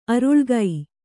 ♪ aruḷgai